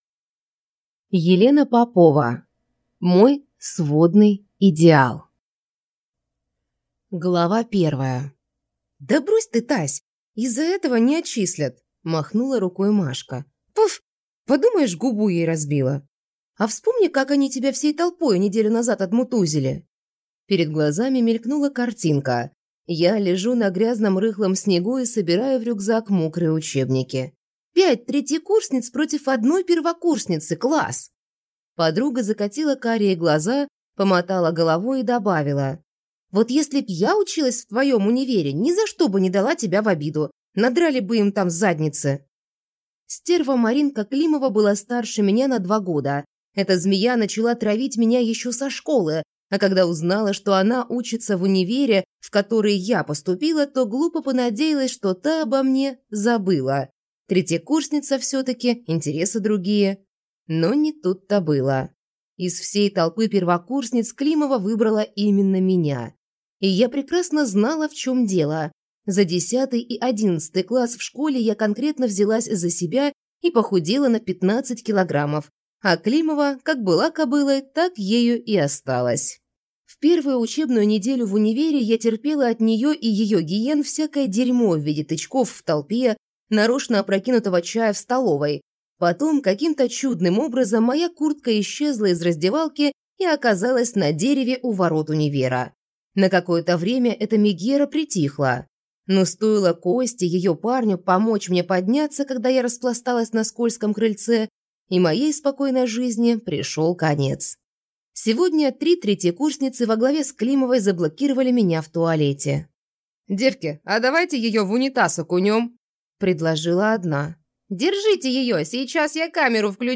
Аудиокнига Мой сводный идеал | Библиотека аудиокниг